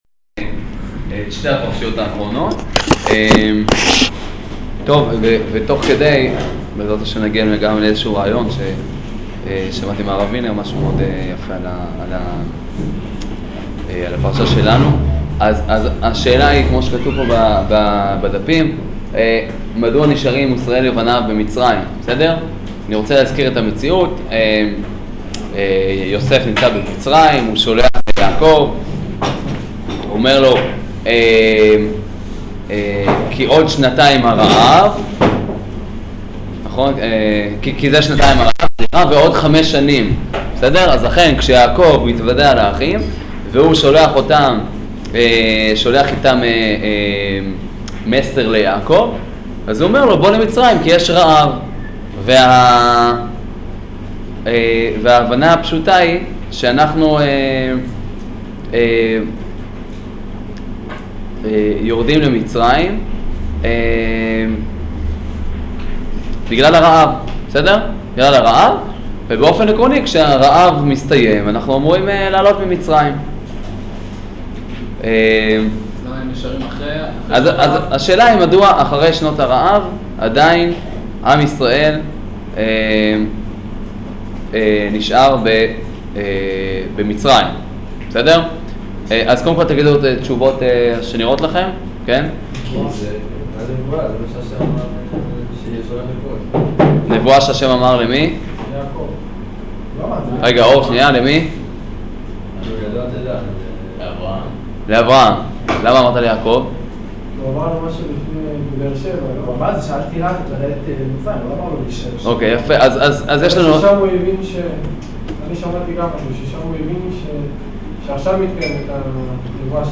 שיעור פרשת ויחי